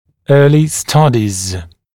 [‘ɜːlɪ ‘stʌdɪz][‘ё:ли ‘стадиз]ранние исследования, проведенные ранее исследования